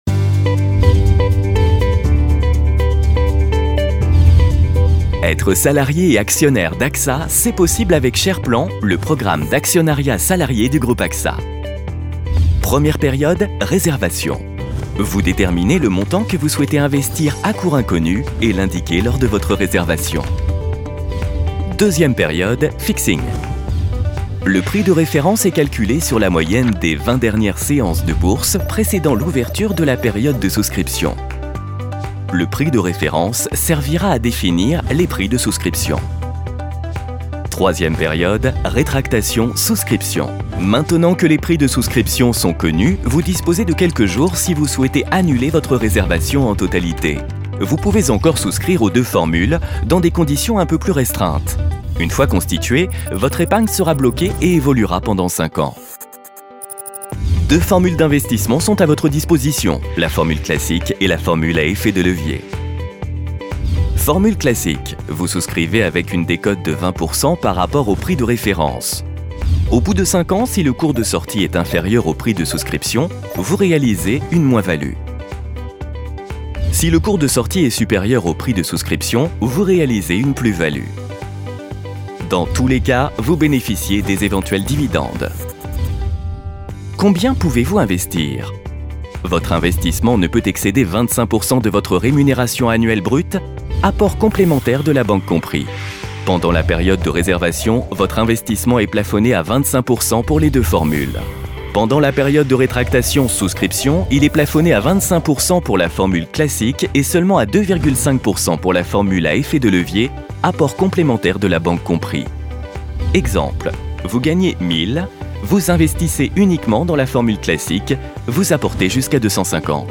comedien voix off institutionnel - Comédien voix off
AXA éloquent, chaleureux